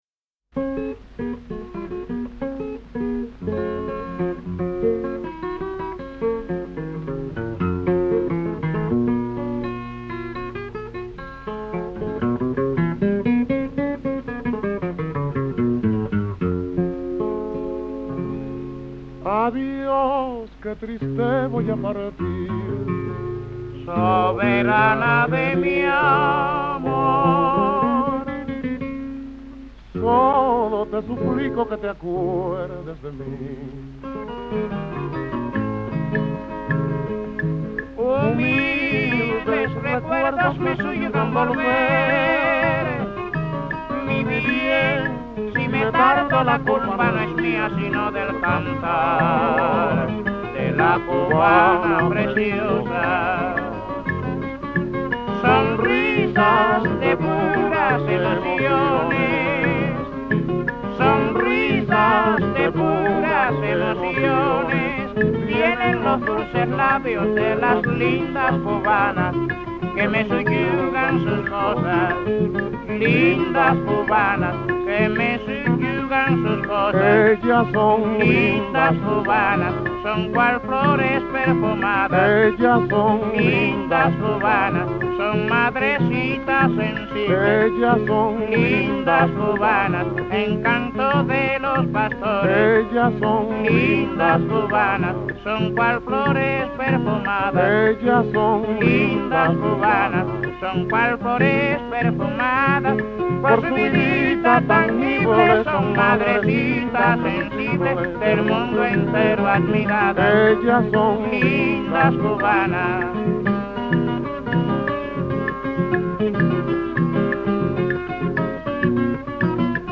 This cut was recorded between 1933-34